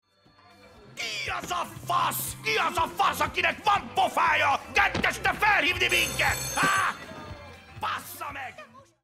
A Hungarian-dubbed clip from Wall Street or Mad Max, perfect for soundboards with international flair or dark humor.